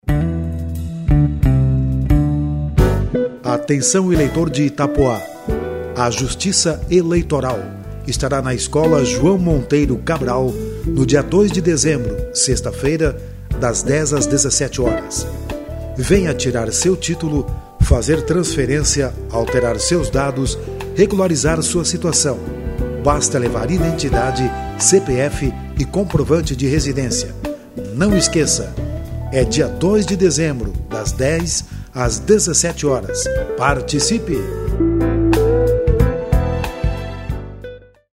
spot_itapoa.mp3